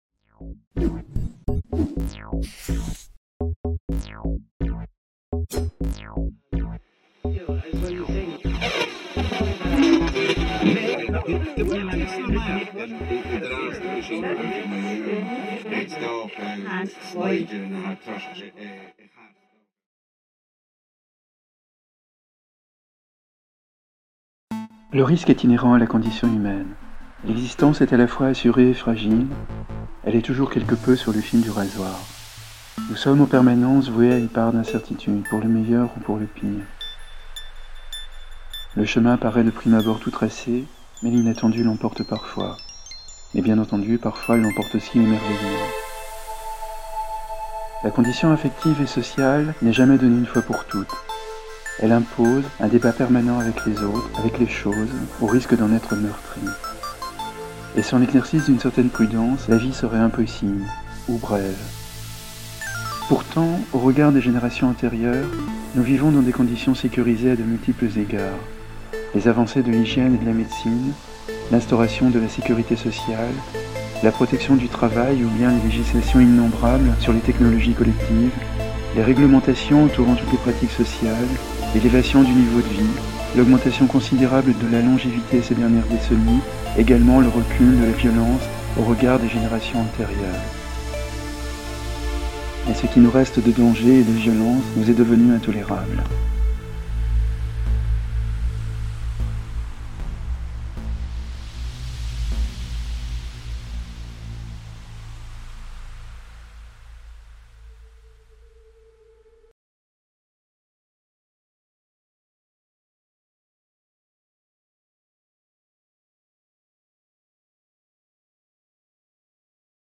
David Le Breton, anthropologue et sociologue, professeur à l'Université de Strasbourg, membre de l'Institut universitaire de France et chercheur au laboratoire Cultures et Sociétés en Europe, s'exprime dans le cadre du Festival des Idées 2017 sur le thème "l'amour du risque".